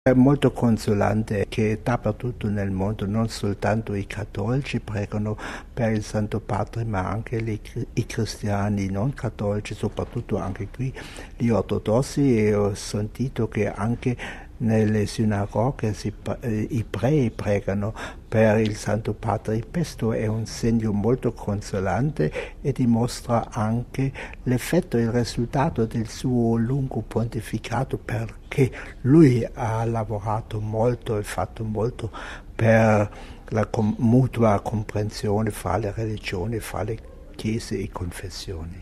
Papa: Dichiarazione del Cardinal Walter Kasper
Home Archivio 2005-04-01 21:58:01 Papa: Dichiarazione del Cardinal Walter Kasper Il Cardinale Walter Kasper, Presidente del Pontificio Consiglio per la Promozione dell'Unità dei Cristiani, ha rilasciato dalla Bulgaria, la seguente dichiarazione : All the contents on this site are copyrighted ©.